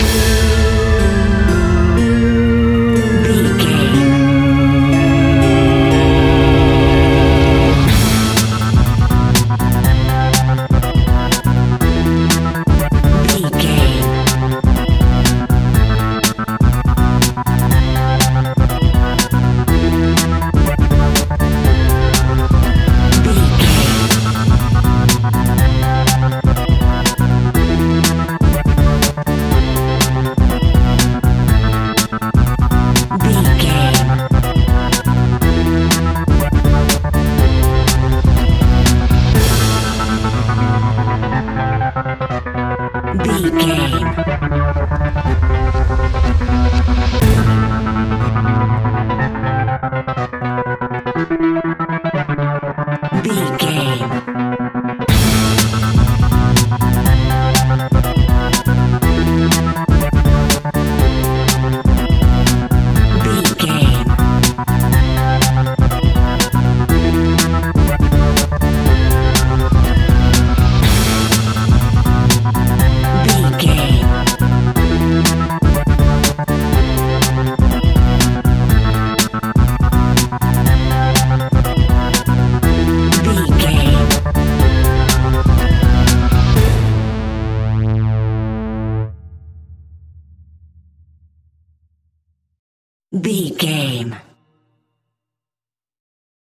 Aeolian/Minor
DOES THIS CLIP CONTAINS LYRICS OR HUMAN VOICE?
WHAT’S THE TEMPO OF THE CLIP?
scary
ominous
eerie
groovy
funky
electric organ
synthesiser
drums
strings
percussion
spooky
horror music